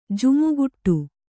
♪ jumuguṭṭu